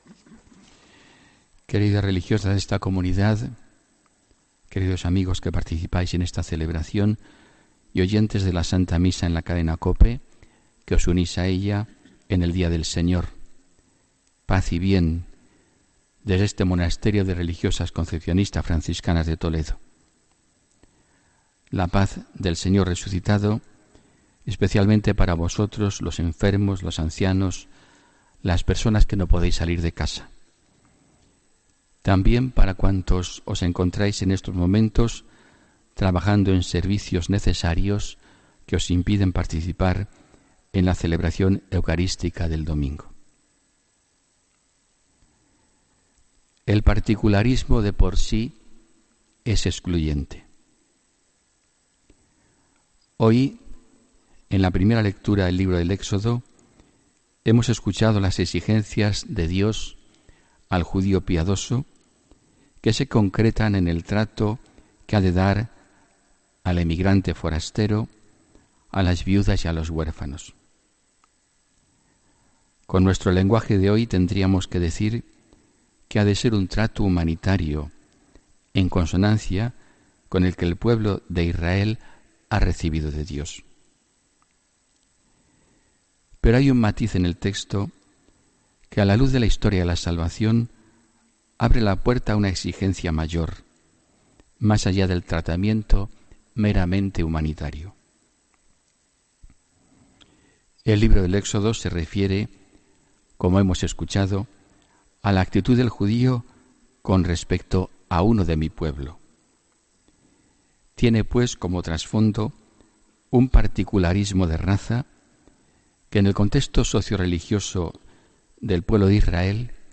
Homilía del domingo 29 de octubre de 2017